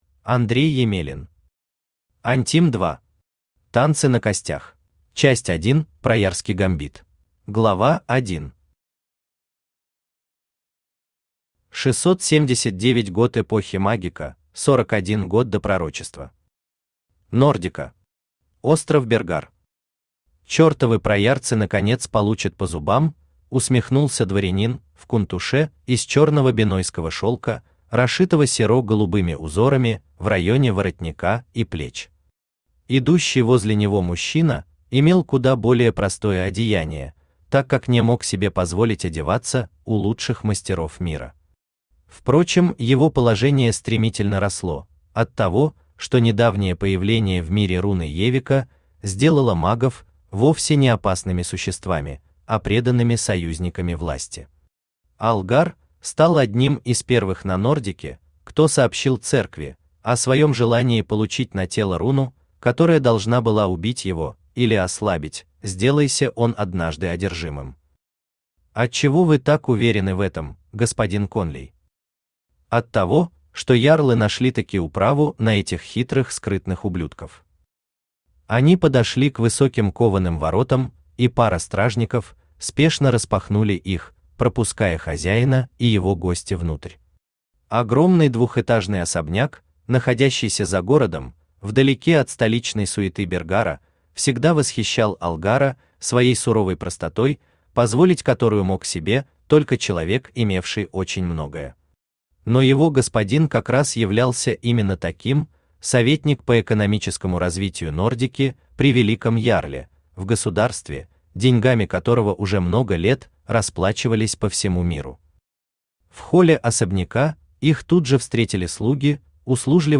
Аудиокнига Антим 2. Танцы на костях | Библиотека аудиокниг
Танцы на костях Автор Андрей Емелин Читает аудиокнигу Авточтец ЛитРес.